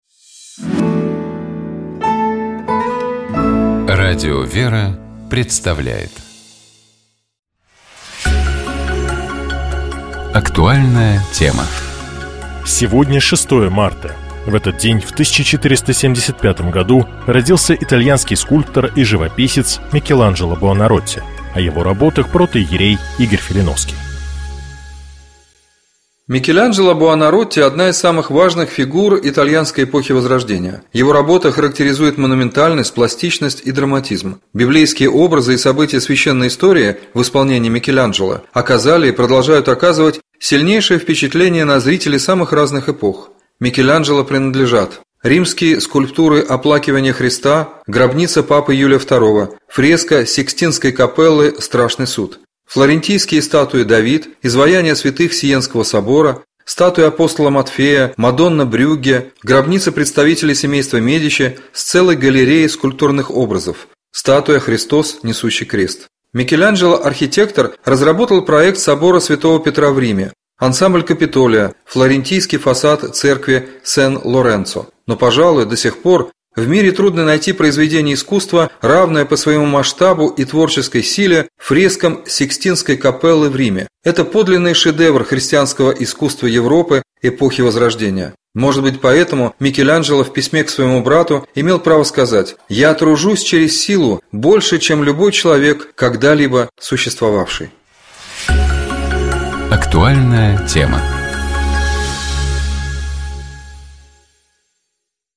О его работах — протоиерей